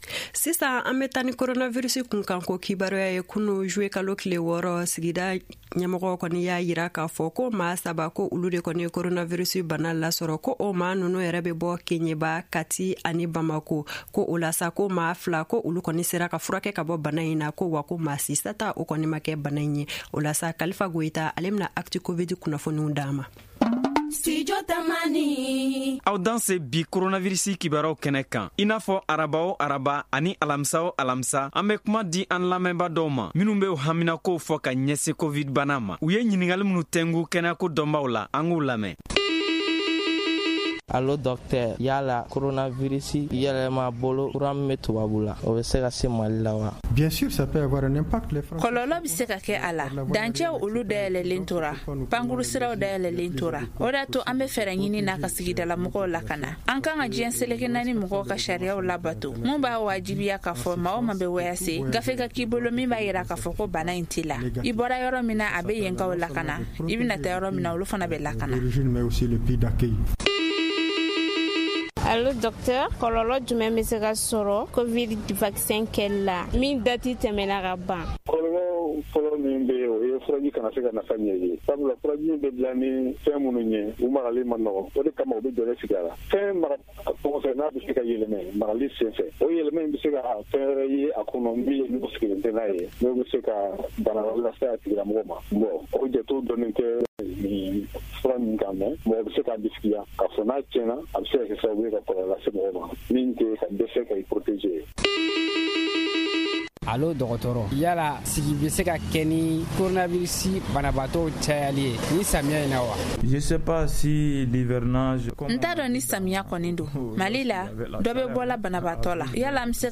Soyez les bienvenus à votre rubrique consacrée à l’actualité du coronavirus « ACTU- COVID ». Comme tous les mercredis et jeudis nous donnons la parole aux auditeurs qui posent des questions par rapport à leurs préoccupations liées à la covid19 avec des les réponses des spécialistes de la santé.